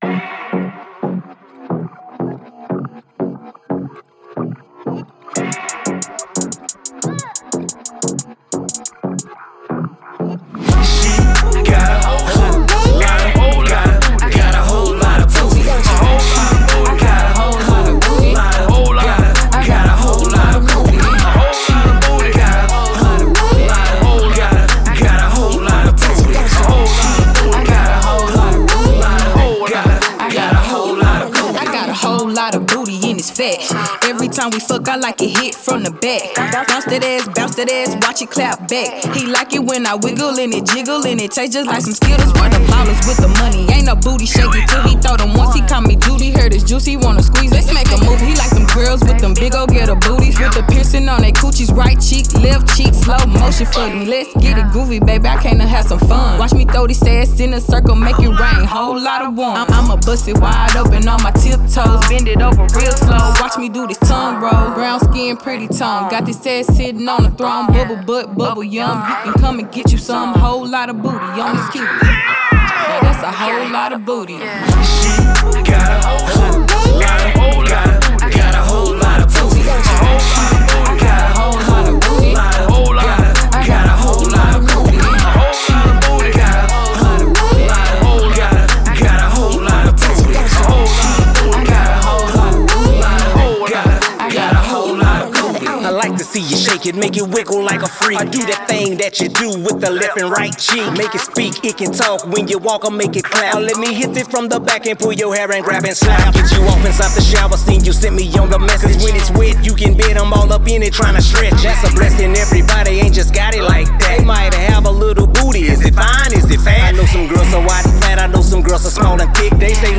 Description : Hot twerk song